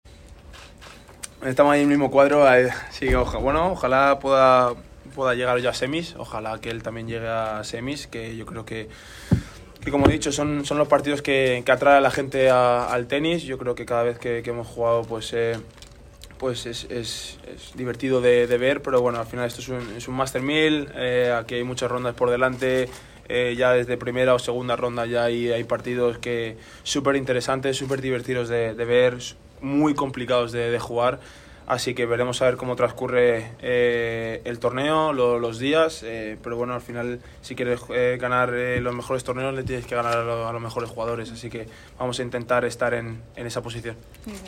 Carlos Alcaraz habla para La W